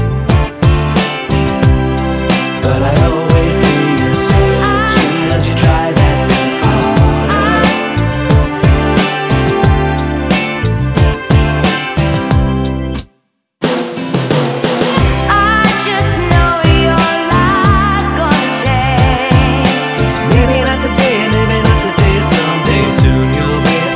One Semitone Up Pop (2000s) 3:33 Buy £1.50